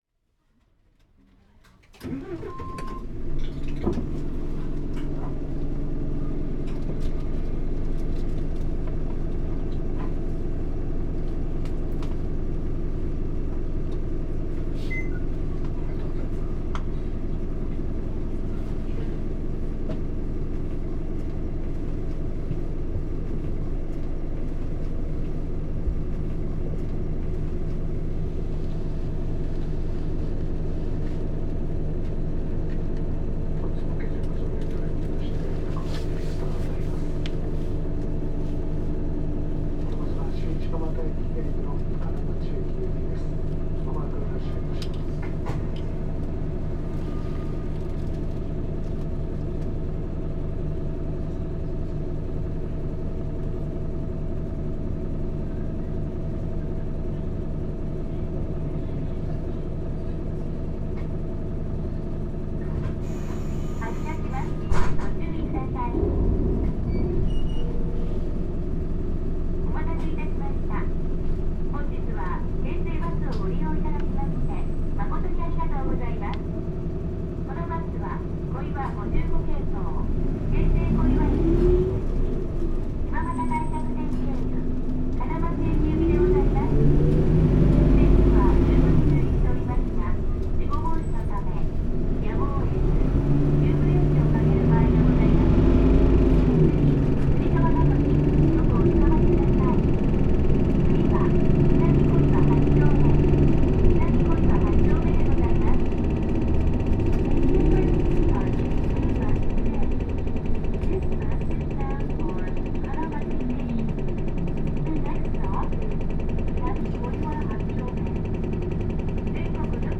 京成バス 日野 KL-HU2PREA ・ 走行音(全区間) (29.5MB*) 収録区間：香澄団地線 幕11系統 新習志野駅→幕張本郷駅 KL代のワンステブルーリボンシティ。
篭ったようなエンジン音が特徴で、音が小さいため他の音に掻き消されがち。シフトチェンジの時のFFシフトの音がよく響いている。収録の走行音の運転士が丁寧に案内される方で、良い雰囲気となっている。